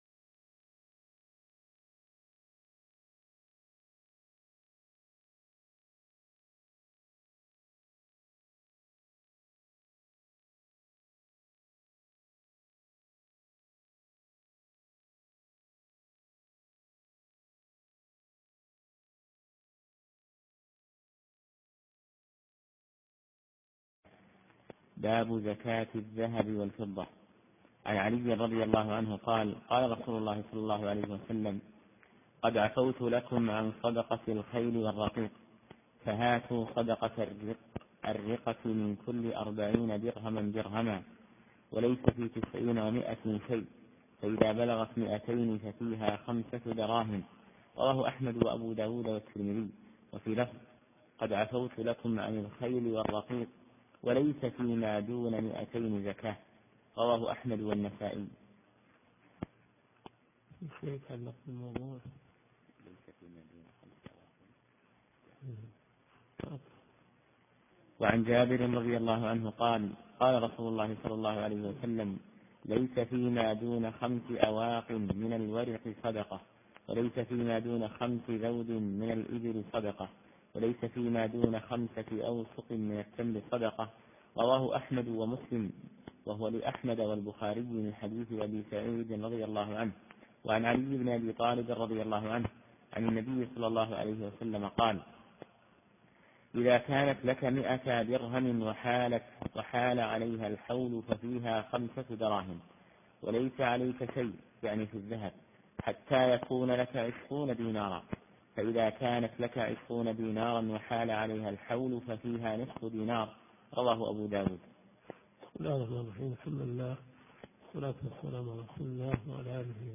دورة صيفية في مسجد معاذ بن جبل .